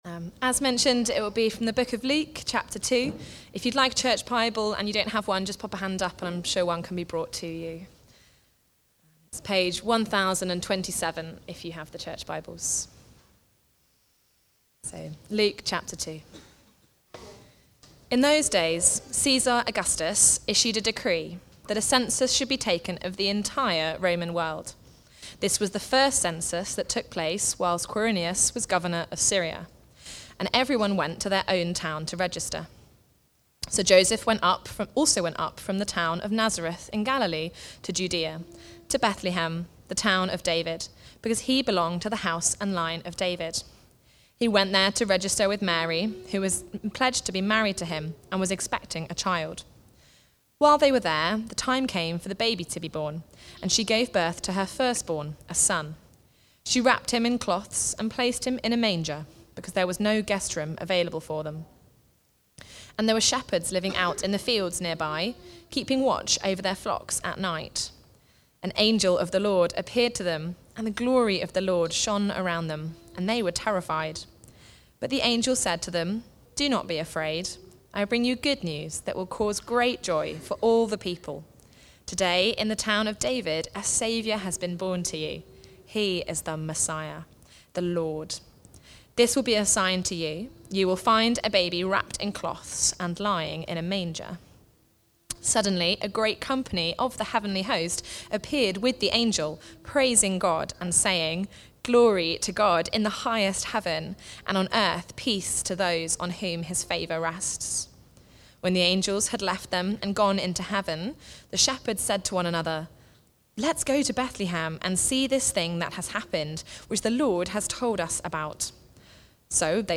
Preaching
Joy (Luke 2:1-20) Recorded at Woodstock Road Baptist Church on 25 December 2025.